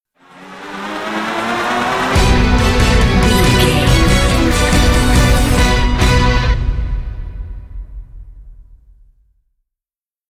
Epic / Action
Aeolian/Minor
strings
cello
violin
percussion
driving drum beat